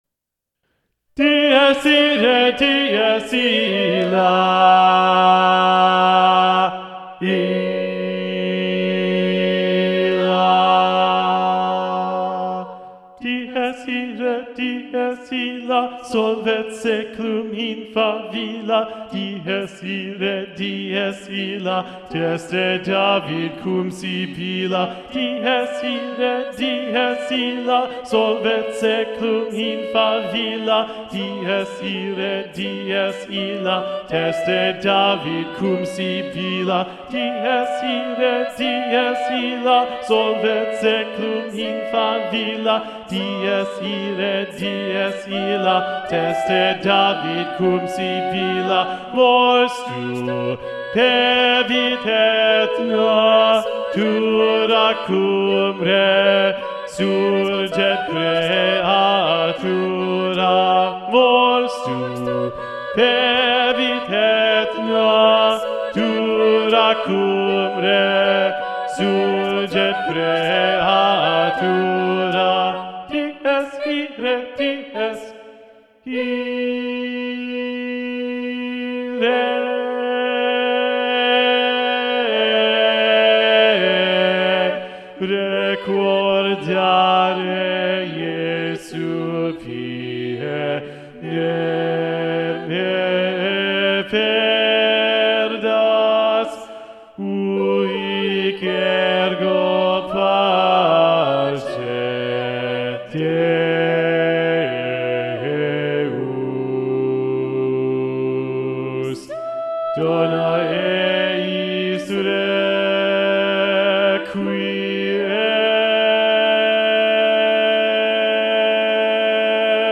- Œuvre pour chœur à 8 voix mixtes (SSAATTBB)
SATB Bass 1 Predominant